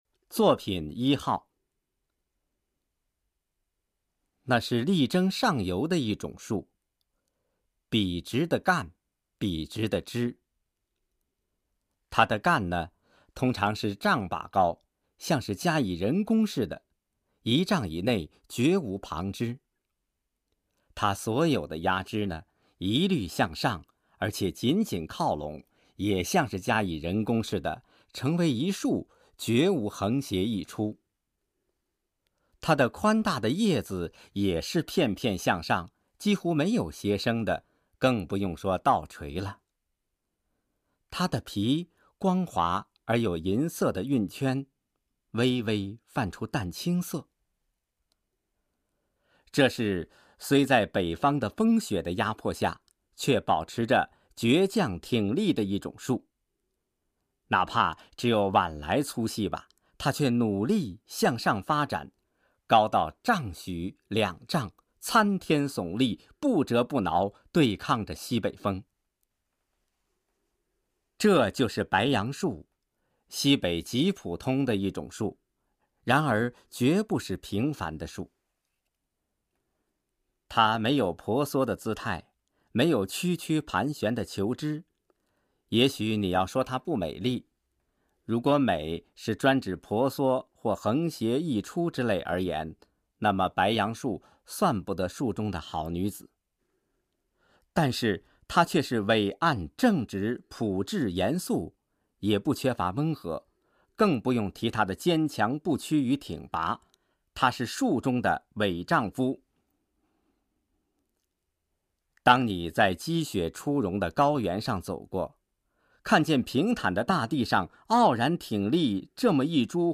当前位置：首页  教学服务与管理  语言文字  普通话测试资料  朗读作品
语音提示
2.似的shìde                            10.婆娑pósuō